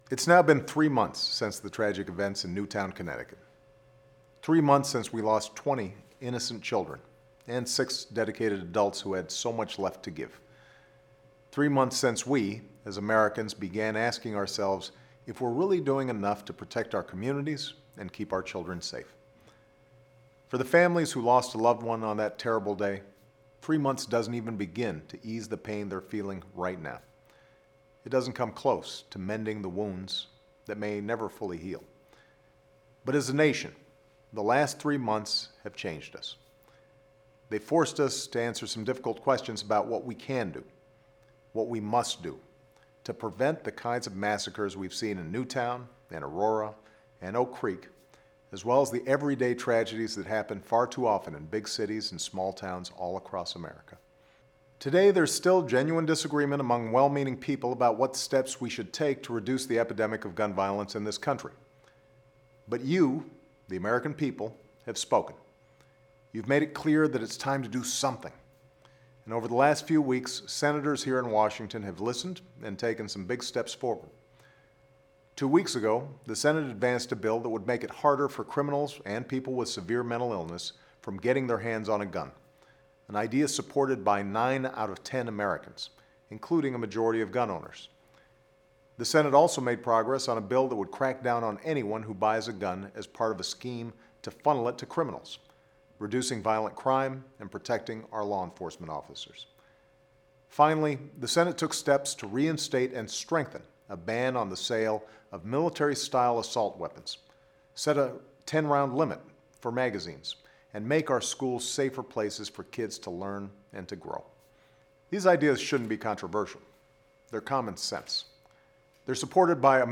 Weekly Address: Helping Protect Our Kids by Reducing Gun Violence
Three months after the tragic shooting in Newtown, Connecticut, President Obama says that the Senate has taken important steps forward to help protect our kids by reducing gun violence.